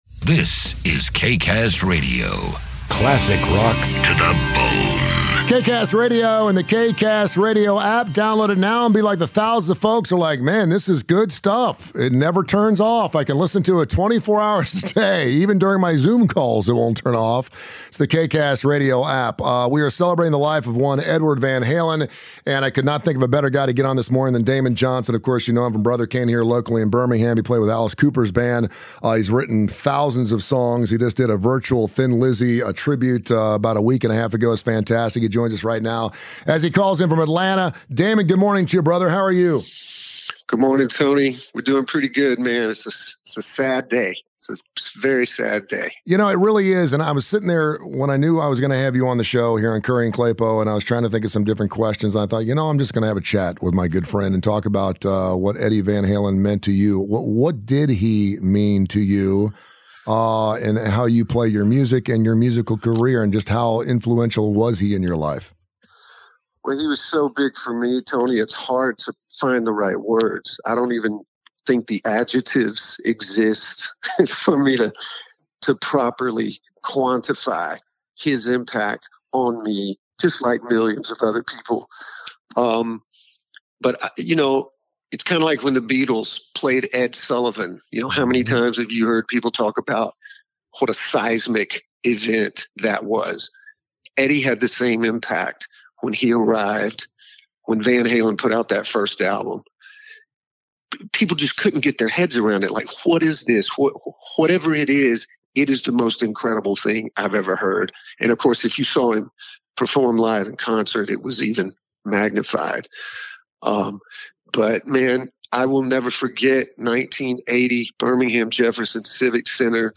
IF YOU MISSED DAMON JOHNSON'S INTERVIEW THIS MORNING, YOU CAN LISTEN TO IT HERE.